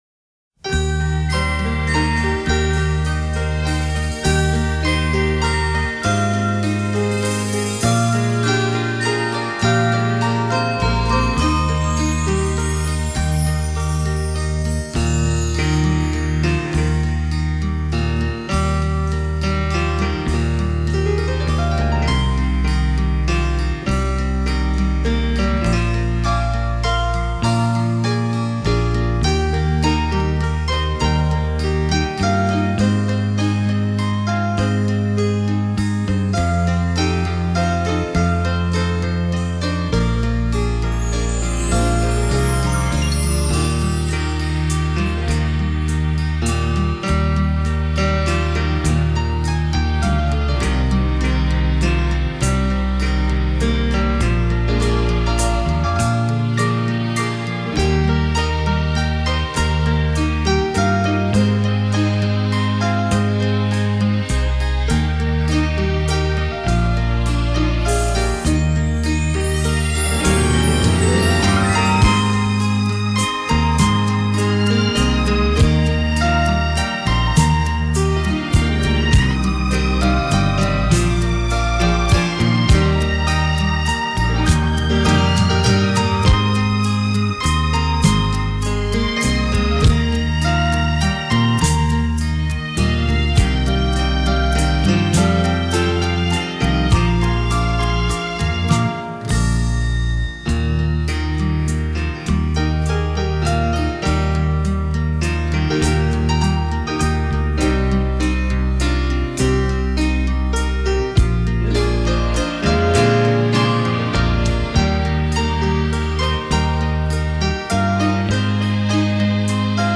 仿佛回到过往的悠悠岁月